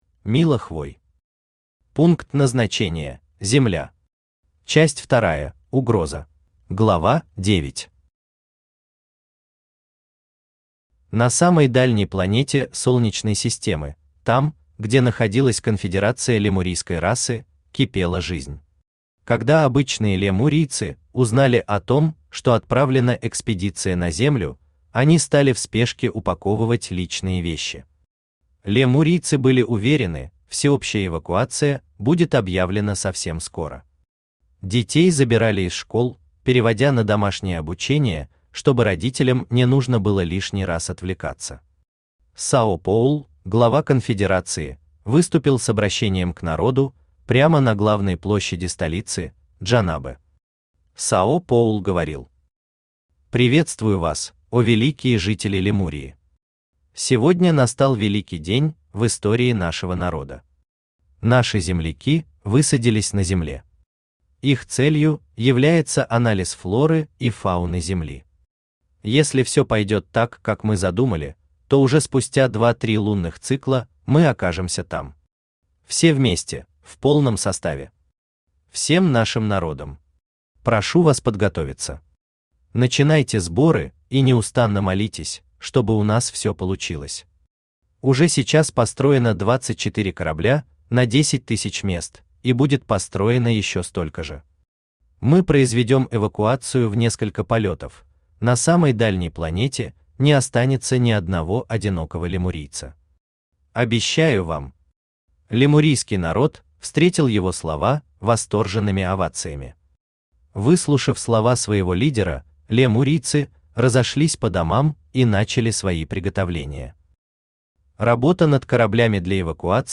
Аудиокнига Пункт Назначения: Земля. Часть Вторая: Угроза | Библиотека аудиокниг
Часть Вторая: Угроза Автор Мила Хвой Читает аудиокнигу Авточтец ЛитРес.